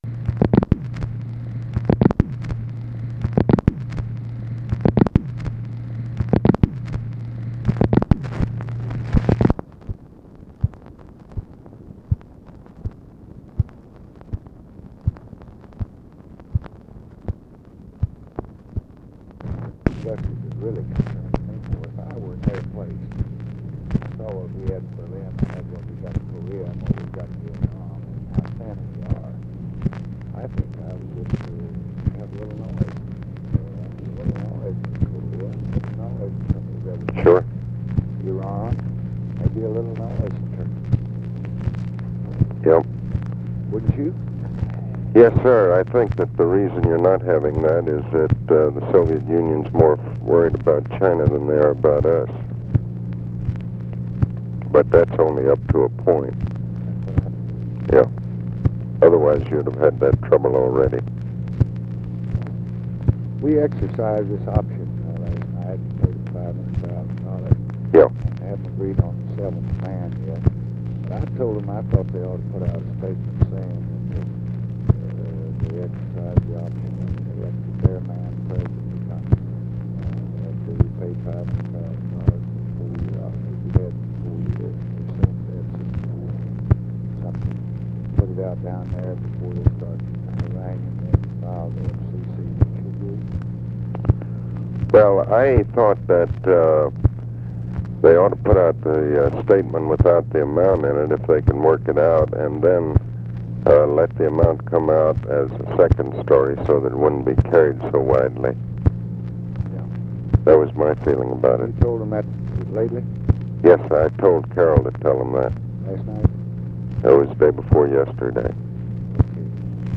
Conversation with ABE FORTAS, January 26, 1966
Secret White House Tapes